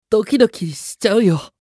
青年ボイス～恋愛系ボイス～
☆★☆★恋愛系☆★☆★